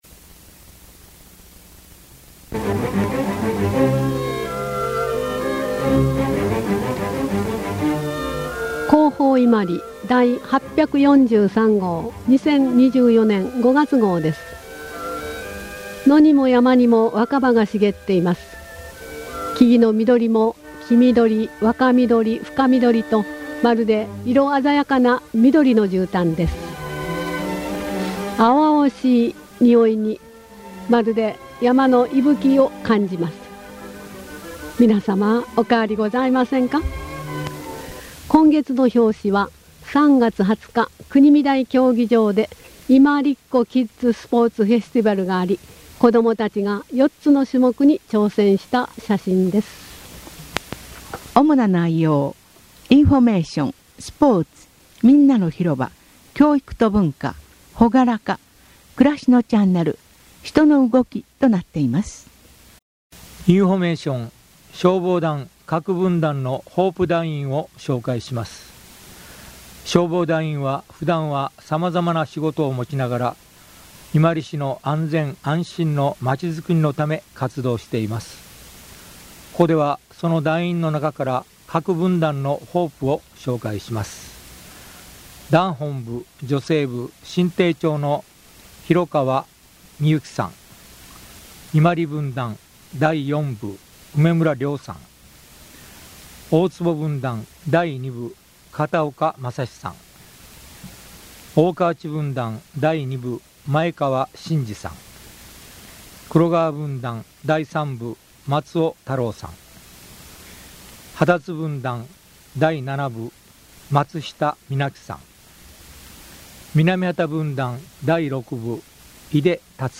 「声の広報伊万里」はこちらです。